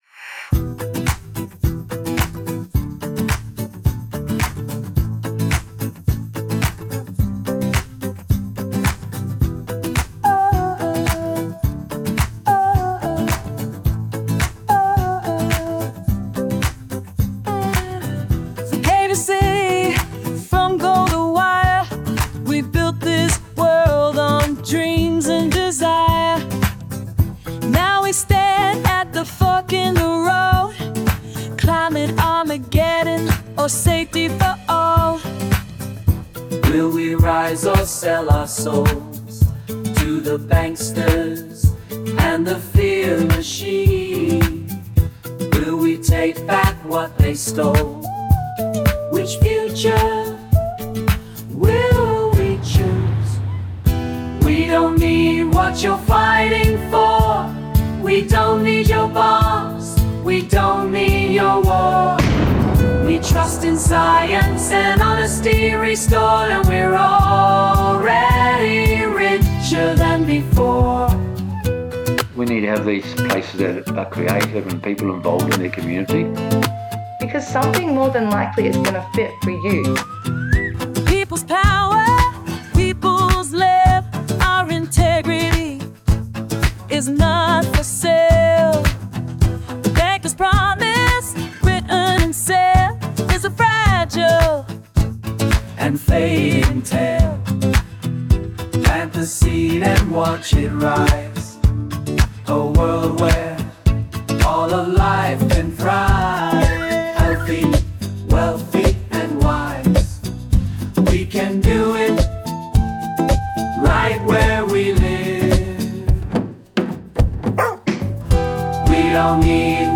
– A defiant anthem calling for people-powered change, rejecting war and greed in favour of integrity, resilience, and a brighter future.